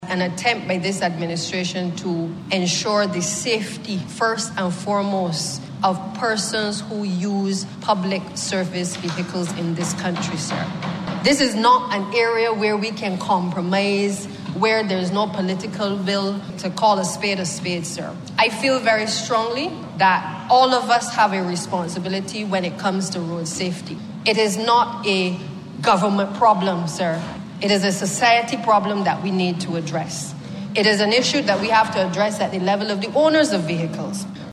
Introducing the Transport Authority amendment bill Miss Bradshaw spoke of receiving several complaints about PSVs from both locals and tourists.